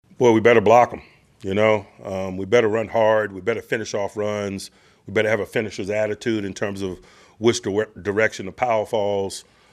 Tomlin held his weekly media event yesterday, and said his sole focus is on the Browns, not on the fact that the Steelers will play two AFC North rivals in five days…Cleveland on Sunday and Cincinnati on Thursday, October 16th.